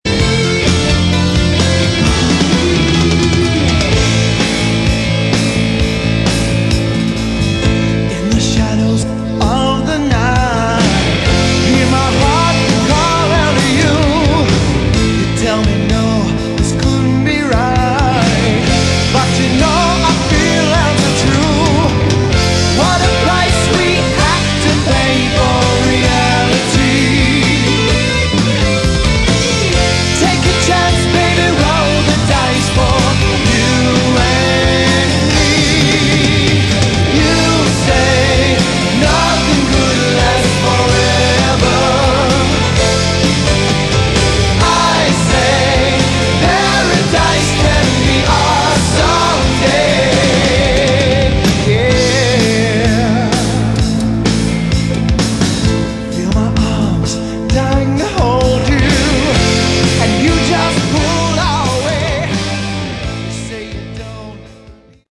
Category: Hard Rock
lead vocals, guitars
keyboards, backing vocals
drums, backing vocals
bass, backing vocals